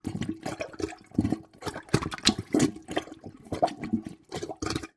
На этой странице собраны звуки, характерные для общественных туалетов: журчание воды, работа сантехники, эхо шагов по кафелю и другие бытовые шумы.
Звук забитого туалета